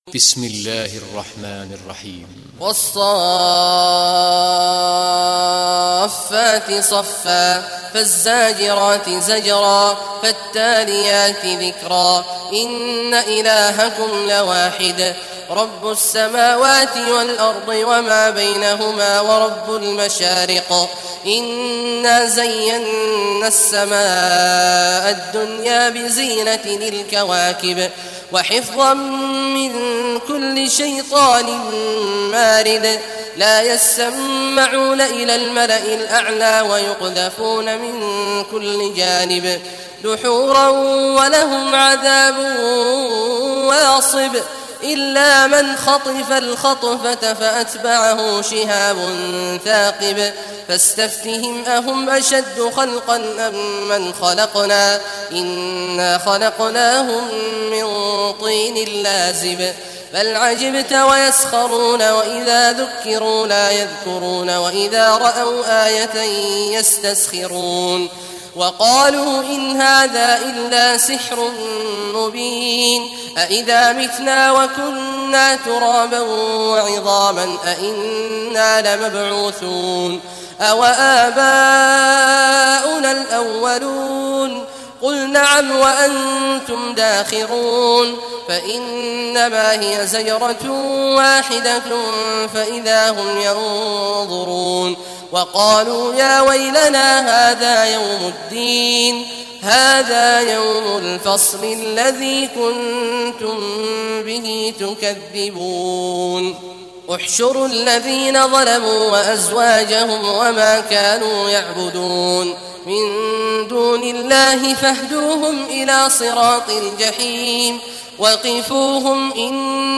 Surah As-Saffat Recitation by Sheikh Awad Juhany
Surah As-Saffat, listen or play online mp3 tilawat / recitation in Arabic in the beautiful voice of Sheikh Abdullah Awad al Juhany.